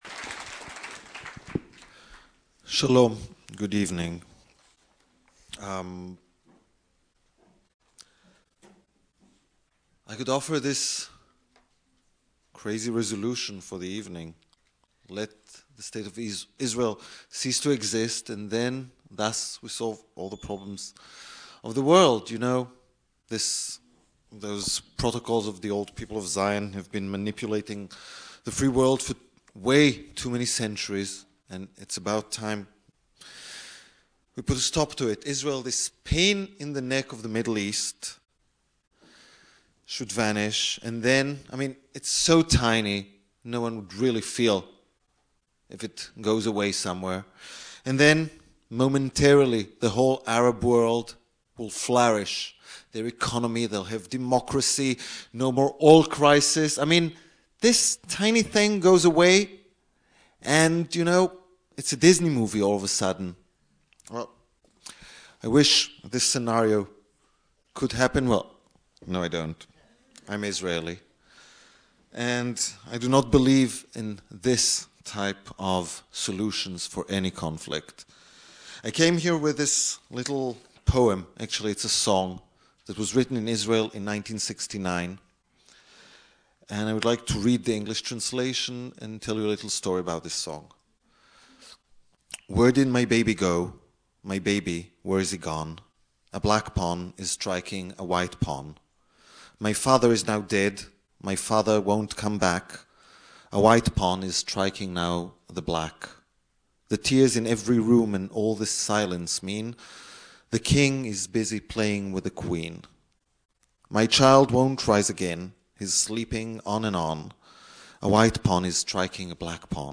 On Tuesday night 10/24/00 there was a discussion at UCSC about the recent outbreak of violence in Israel and Palestinian territories.
Audio - UCSC Panel Discussion about the Israel/Palestinian Conflict (4 of 5)